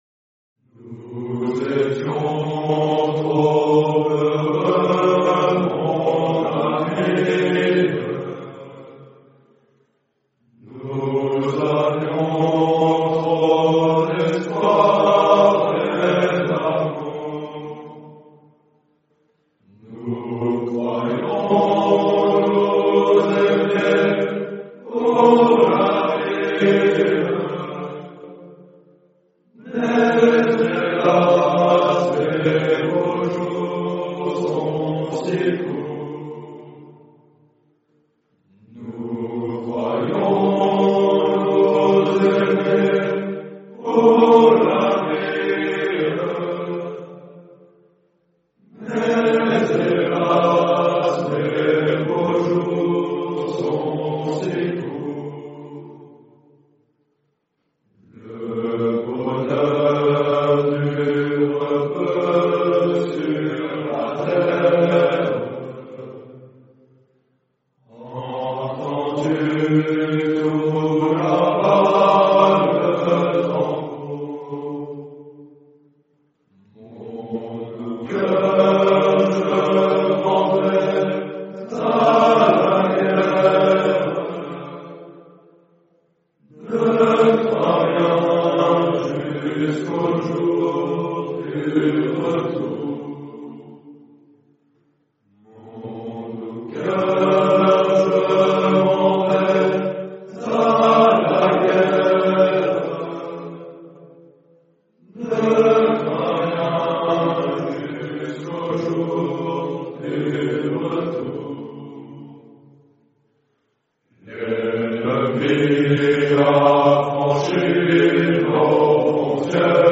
En Suisse, une mélodie poignante, « Les Adieux suisses », continue encore aujourd’hui de porter la mémoire de ces hommes fidèles jusque dans les replis du cœur national.
Chaque strophe résonne comme une prière mélancolique du soldat quittant sa terre natale, guidé par l’honneur.
Bien que son auteur demeure anonyme, « Les Adieux suisses » s’inscrit dans une tradition populaire profondément ancrée dans l’histoire helvétique.